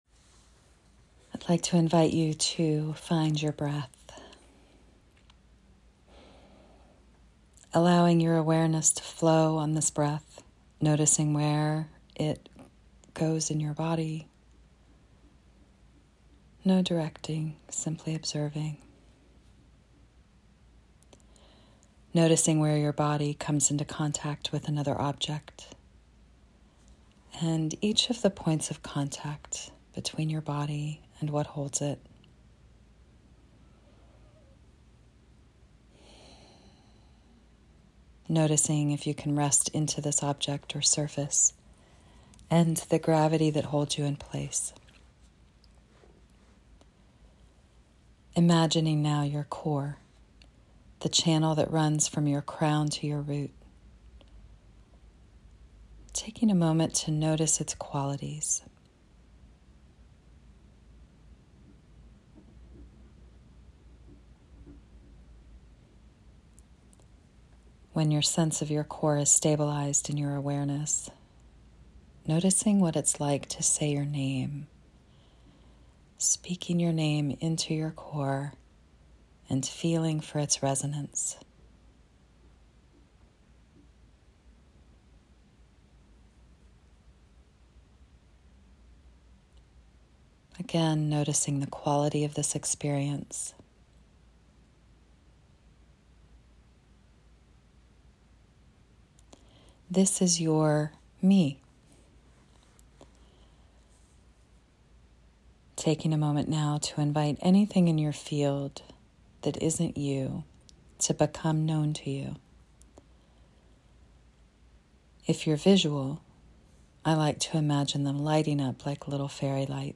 MeNotMeMeditation.m4a